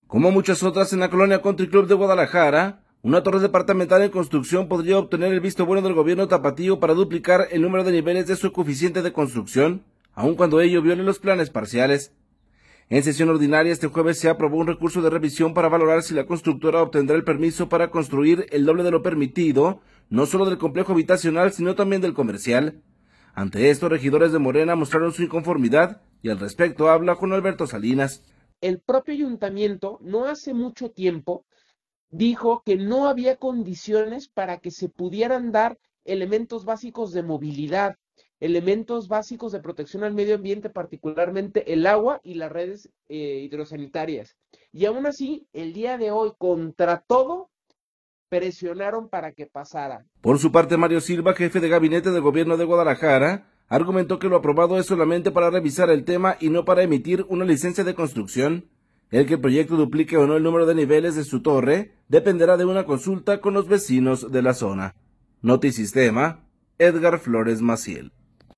En sesión ordinaria, este jueves se aprobó un recurso de revisión para valorar si la constructora obtendrá el permiso para construir al doble de lo permitido no solo del complejo habitacional sino también del comercial. Ante esto, regidores de Morena mostraron su inconformidad y al respecto habla Juan Alberto Salinas.